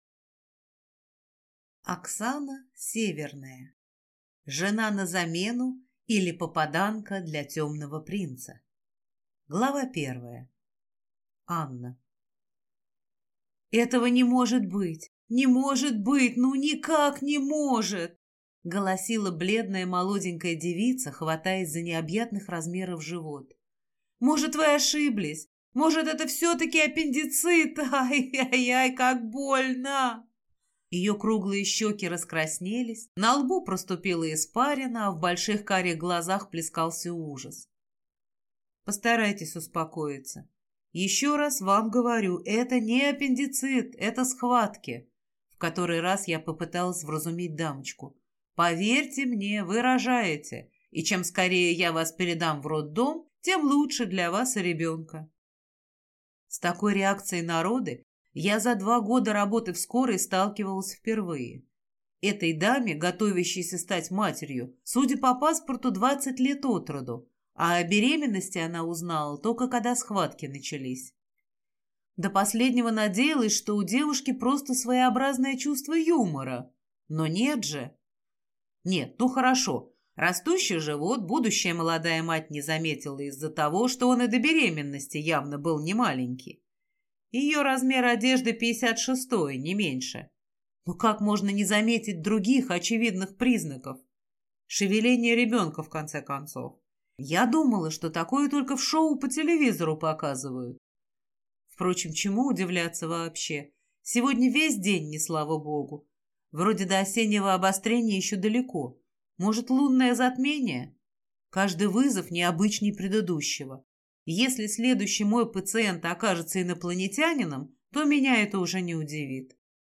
Аудиокнига Жена на замену, или Попаданка для темного принца | Библиотека аудиокниг